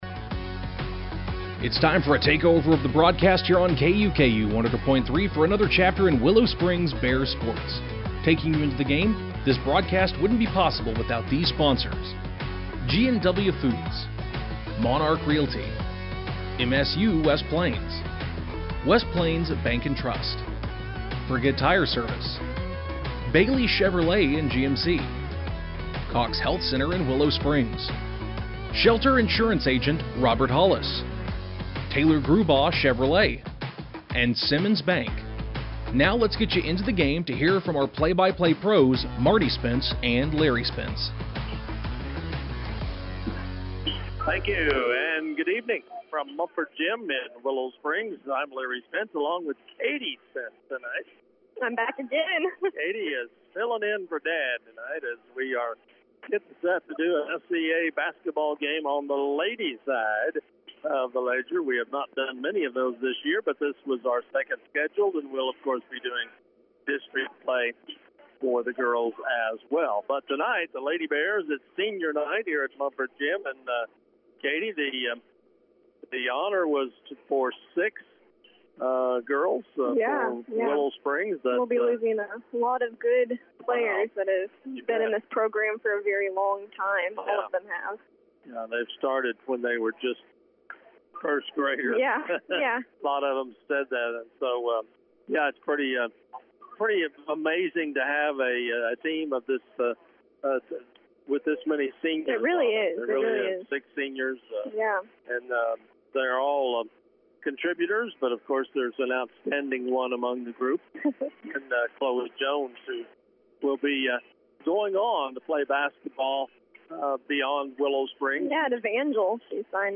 Game Audio Below: The lady bears controlled the tipoff and took a quick shot but unfortunately missed.